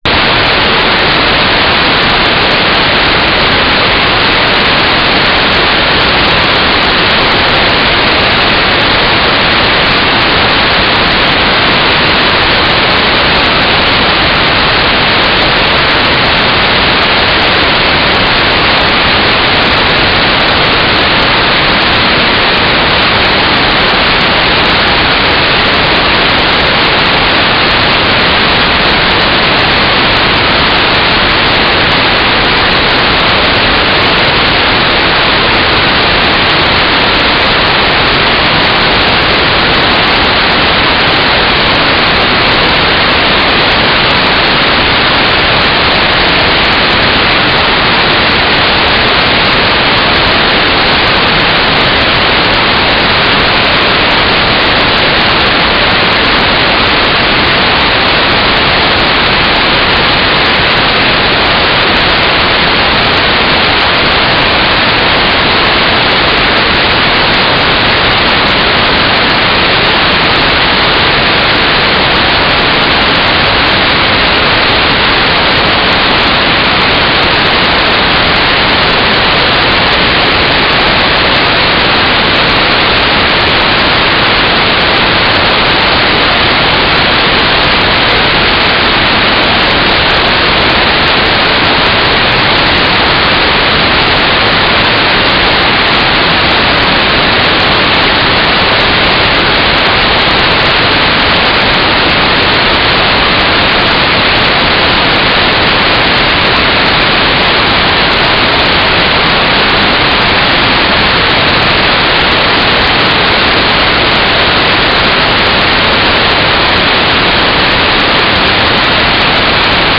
"transmitter_description": "Mode U - FSK4k8 - TLM",
"transmitter_mode": "FSK AX.100 Mode 5",